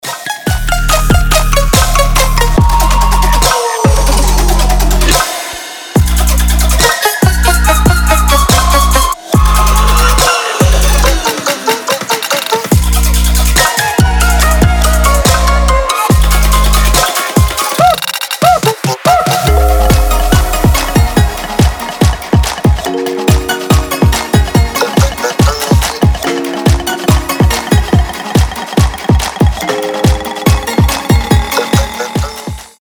• Качество: 320, Stereo
ритмичные
громкие
забавные
мелодичные
Electronica
jungle terror